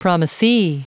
Prononciation du mot promisee en anglais (fichier audio)
Prononciation du mot : promisee